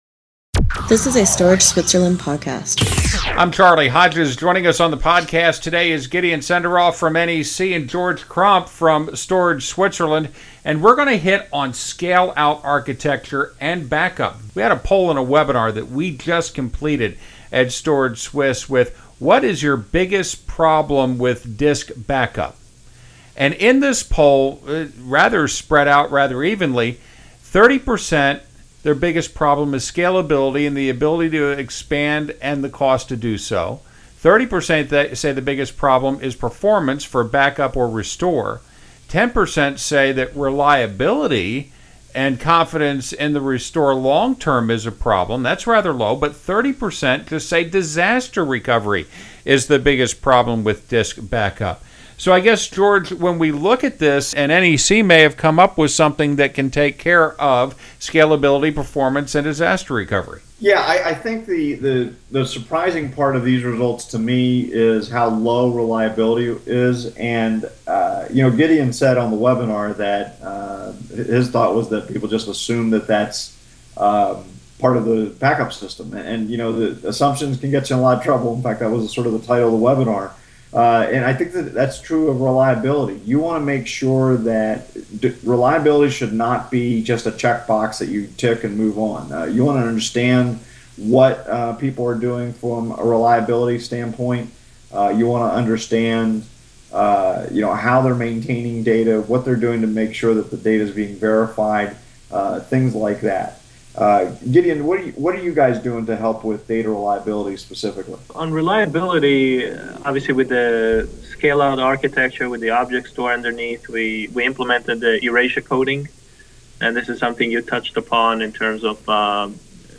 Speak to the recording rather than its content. we got together in the podcast studio to talk about an intriguing discovery from their presentation. That’s the subject of this Storage Swiss Podcast.